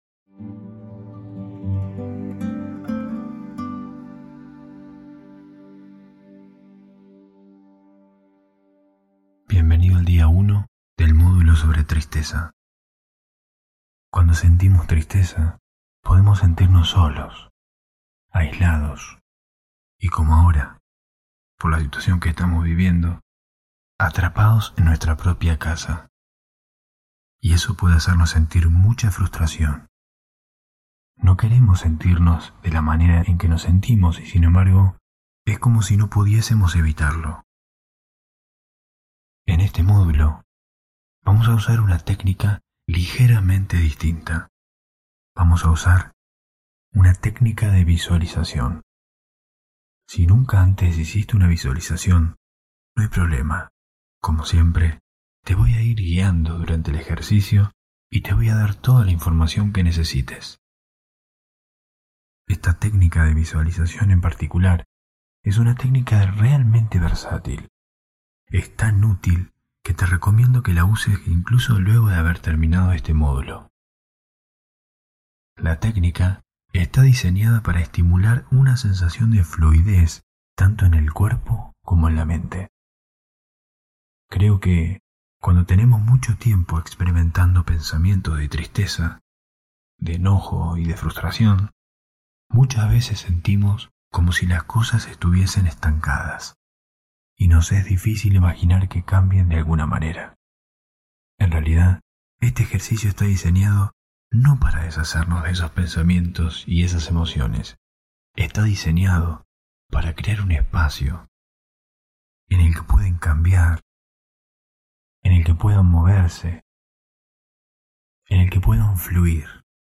Cambiá la forma de relacionarte con la tristeza. Día 1 [Audio 8D. Mejor con auriculares] Hosted on Acast.